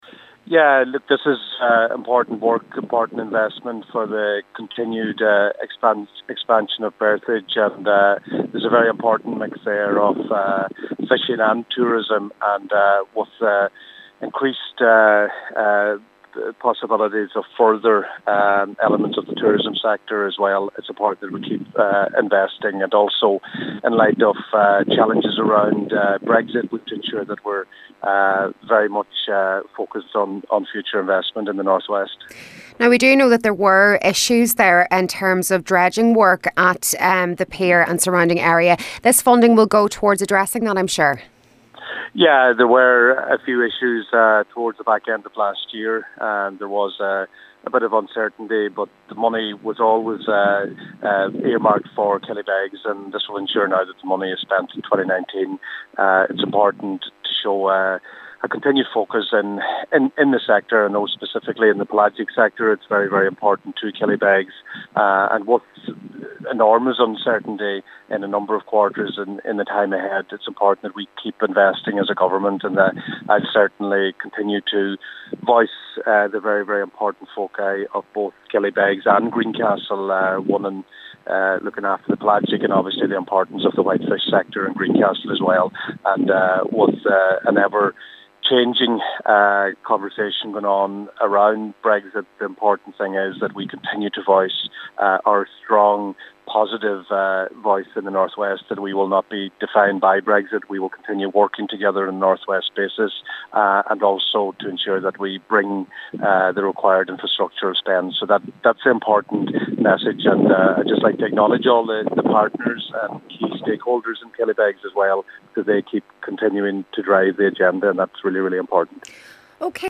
Minister Joe McHugh says the investment will make a huge difference to facilities there: